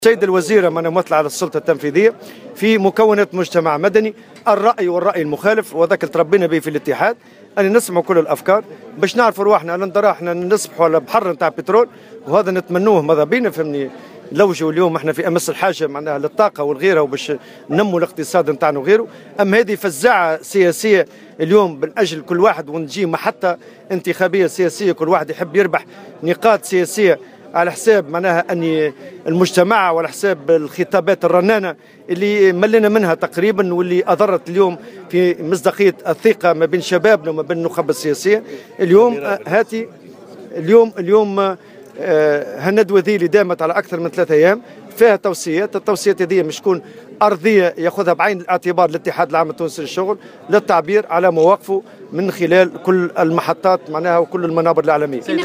على هامش اختتام الندوة النقابية التي نظمتها الجامعة العامة للنفط والمواد الكيميائية التابعة للاتحاد العام التونسي للشغل على امتداد 3 ايام في الحمامات